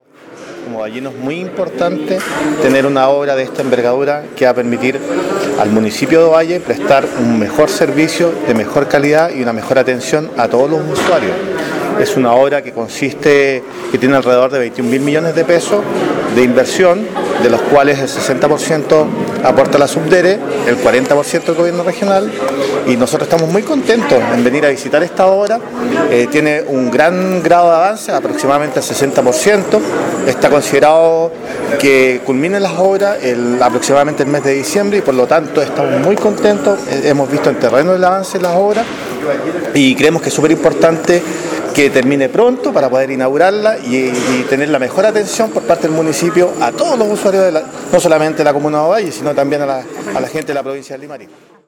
“Una obra de esta envergadura va a permitir al municipio de Ovalle prestar un mejor servicio y de calidad a todos los usuarios”, dijo  el consejero Max Aguirre, mientras que su par  Bernardo Chávez, manifestó que